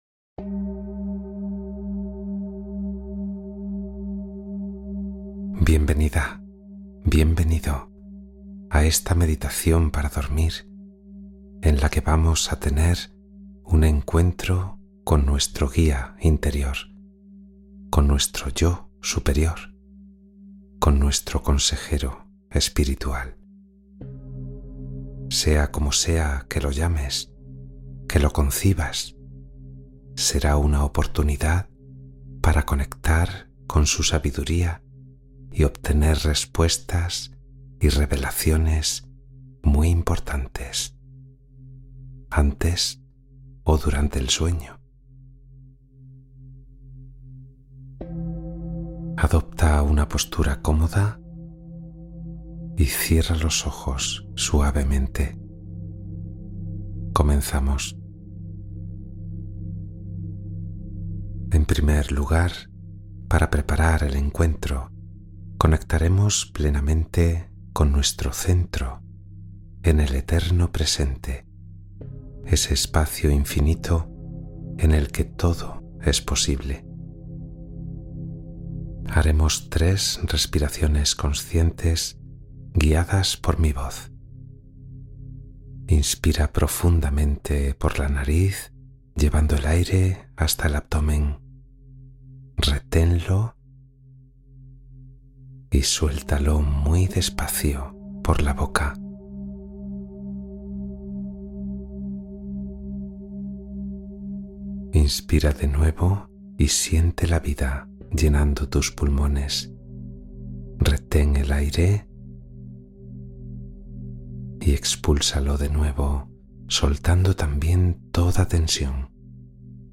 Meditación de Introspección Nocturna para Escuchar a tu Guía Interior